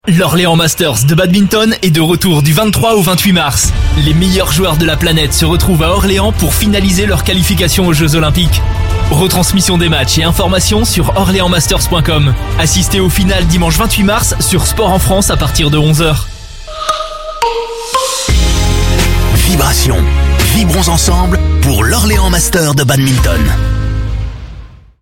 PUB Orléans Master Badminton
Voix off
16 - 40 ans